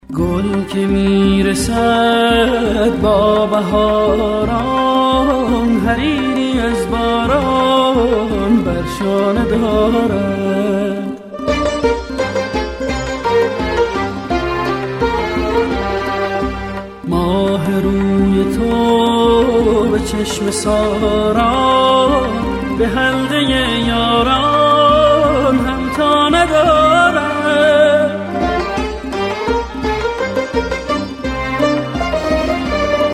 زنگ موبایل زیبا و عاشقانه با کلام